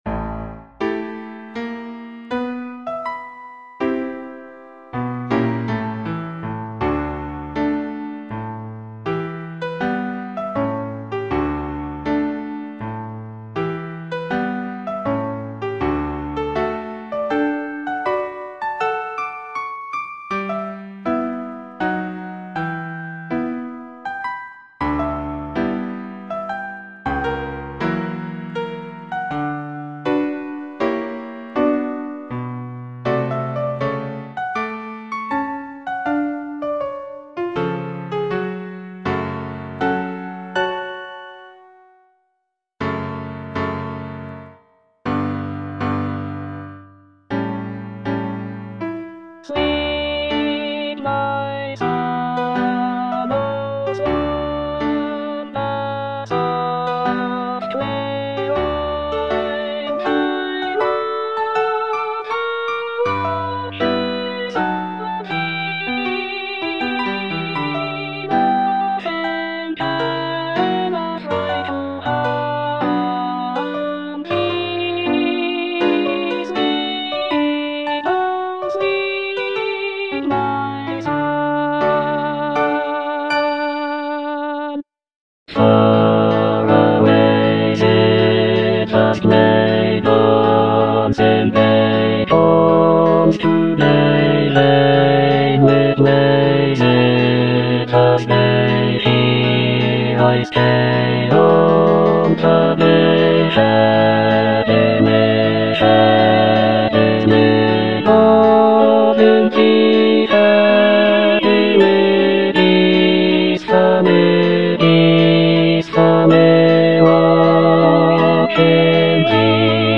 E. ELGAR - FROM THE BAVARIAN HIGHLANDS Lullaby (bass I) (Emphasised voice and other voices) Ads stop: auto-stop